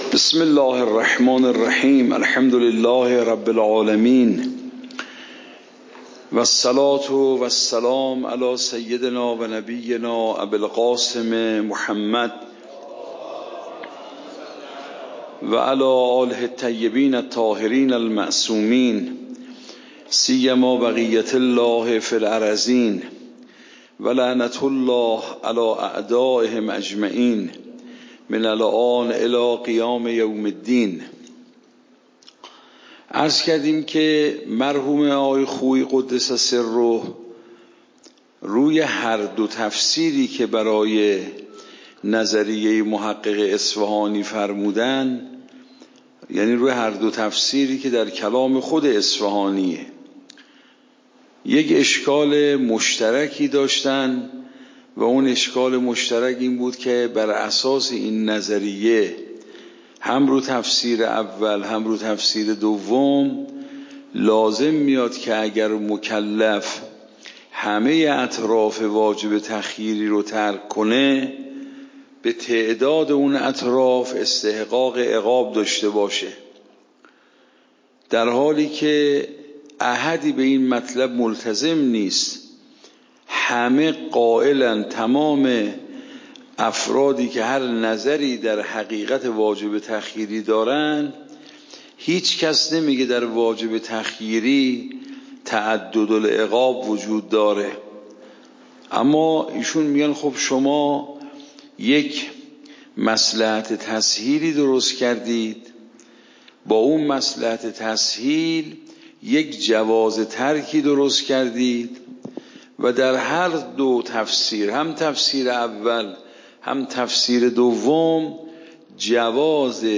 درس بعد واجب نفسی و غیری درس قبل واجب نفسی و غیری درس بعد درس قبل موضوع: واجب نفسی و غیری اصول فقه خارج اصول (دوره دوم) اوامر واجب نفسی و غیری تاریخ جلسه : ۱۴۰۴/۸/۴ شماره جلسه : ۲۶ PDF درس صوت درس ۰ ۱۱۲